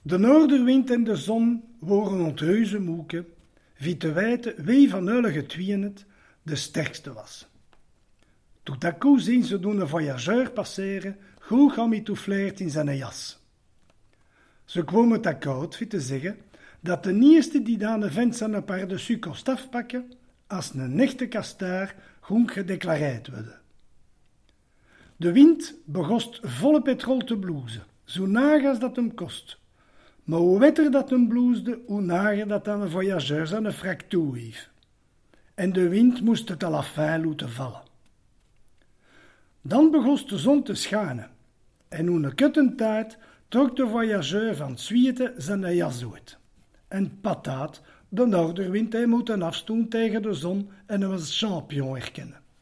Francès Beulemans: Ciddé, vos ploz schoûter èn eredjistrumint del fåve do vî vî tins « Li bijhe et l' solea » e «francès Beulemans», adiercî pol Djåzant atlasse éndjolike des lingaedjes di France et d' avår la .